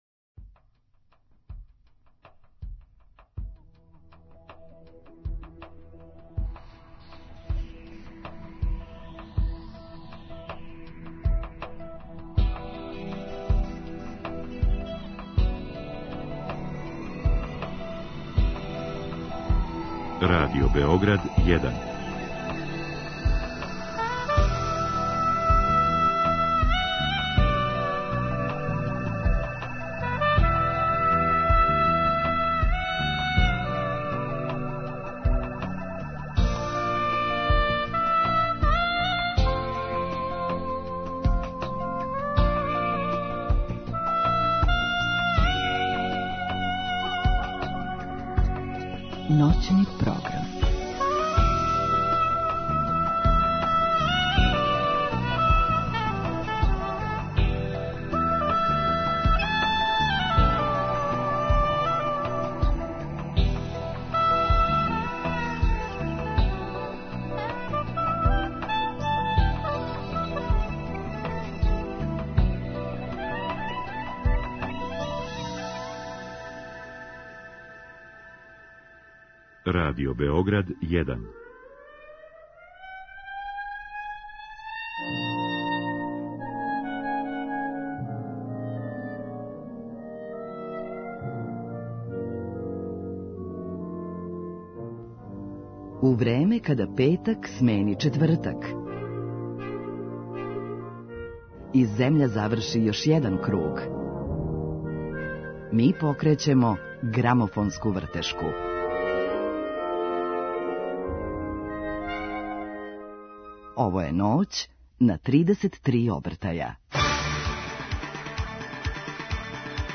У данашњој емисији ћемо слушати неке и испричати музичке приче које се везују за њих.